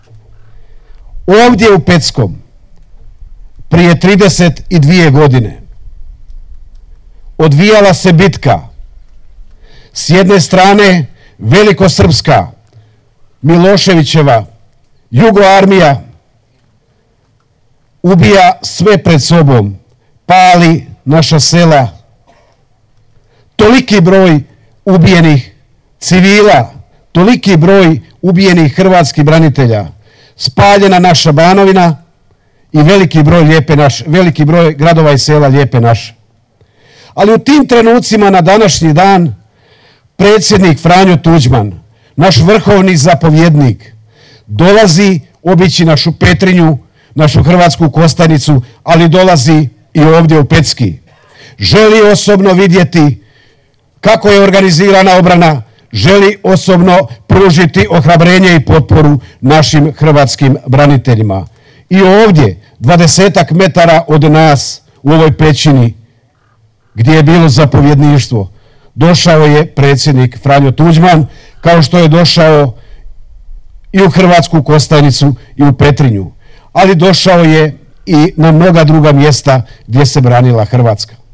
Raduje me što je ovdje danas veliki broj branitelja iz cijele Hrvatske, svi su odali počast i zaslugu našim žrtvama, a istodobno u zajedništvu očuvali snagu koju smo izgradili za vrijeme Domovinskog rata, rekao je u Peckom izaslanik predsjednika Vlade RH, potpredsjednik Vlade, ministar hrvatskih branitelja Tomo Medved